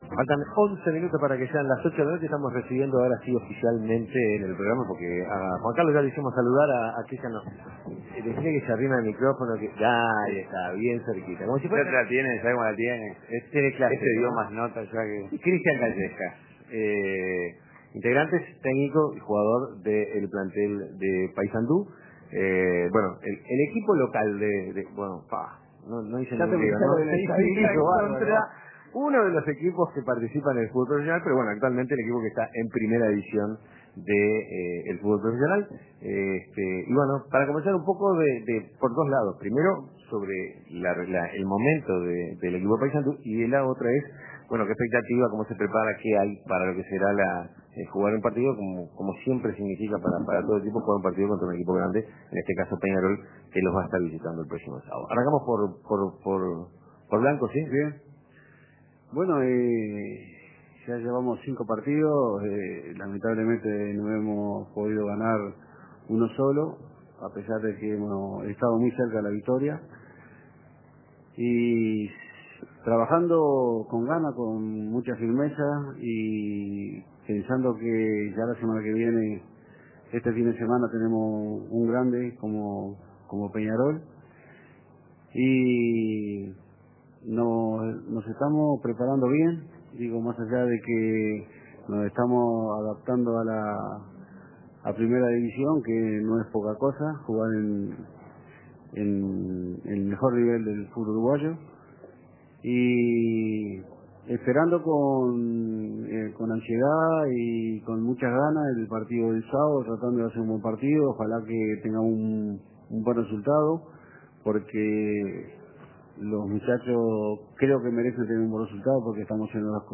Radiodeportivo Entrevista